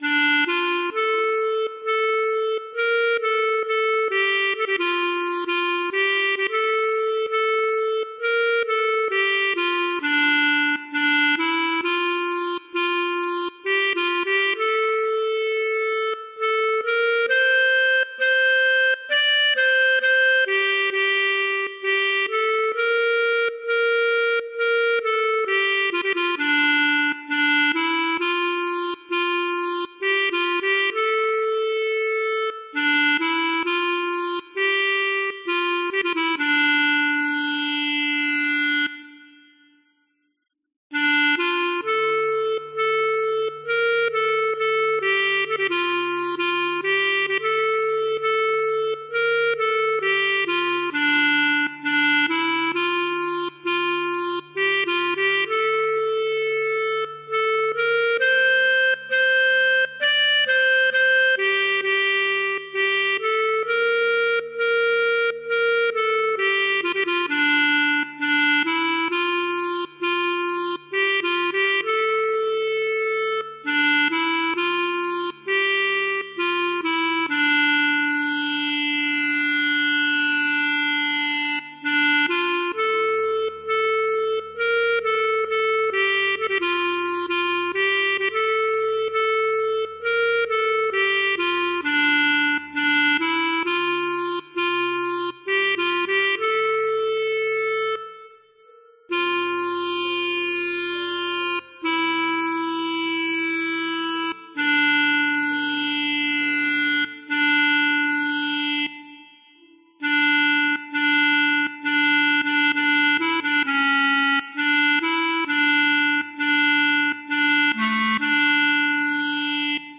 - Hymne du soir en norvégien